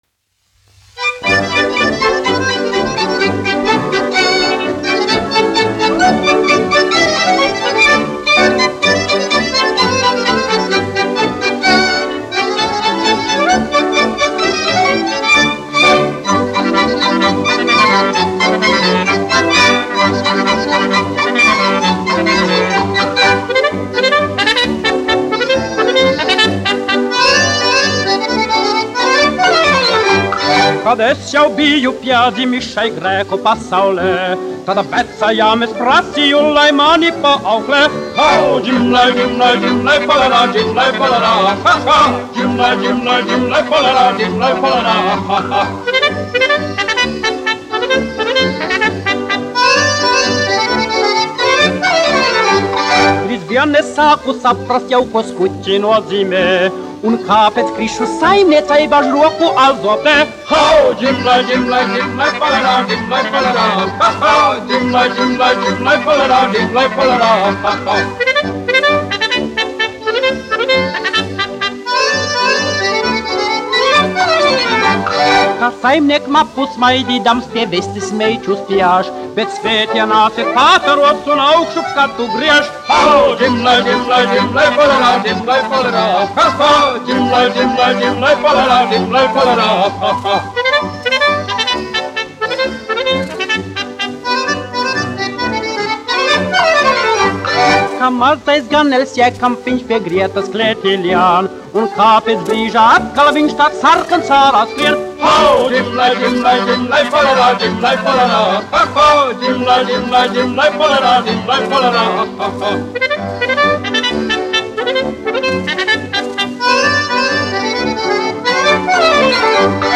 1 skpl. : analogs, 78 apgr/min, mono ; 25 cm
Polkas
Populārā mūzika
Skaņuplate